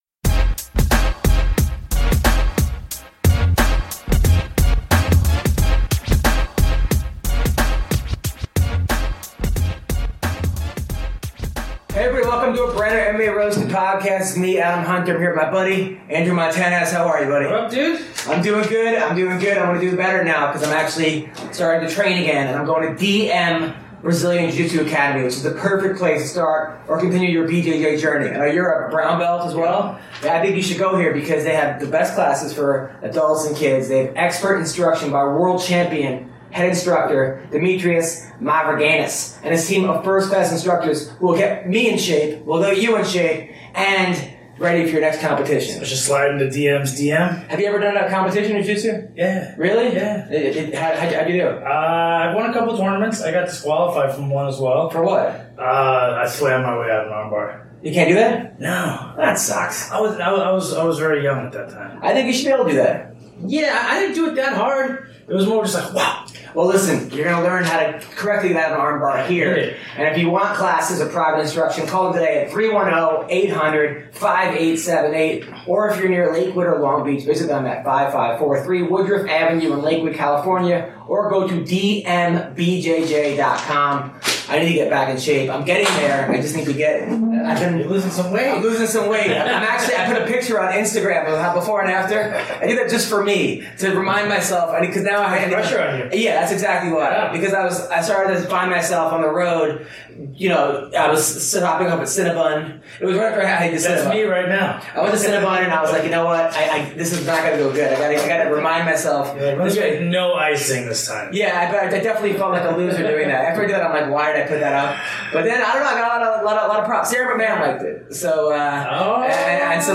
Then Daniel Straus calls in and talks about his recent loss in Bellator and what he needs to do to get back on track. Then Beniel Dariush calls in and talks about his recent fight with Evan Dunham, building homes in Haiti, his GF and her mom and more.